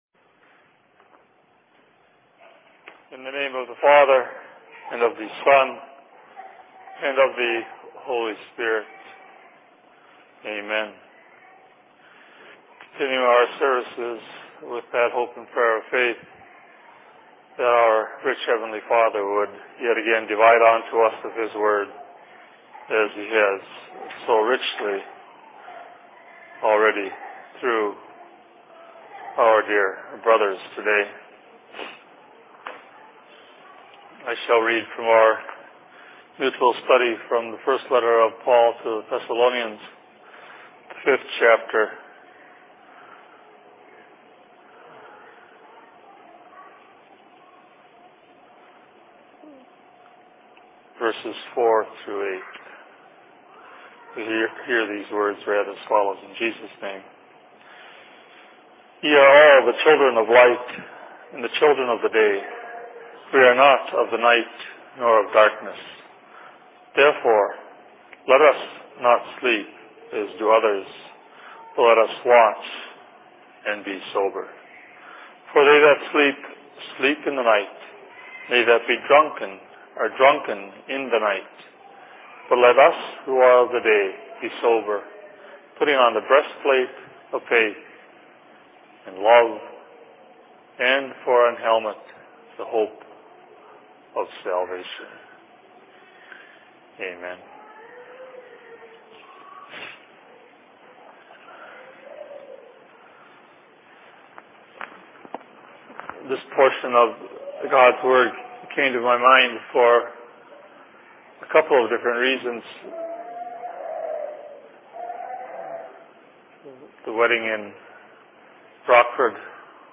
Sermon in Minneapolis 27.08.2006
Location: LLC Minneapolis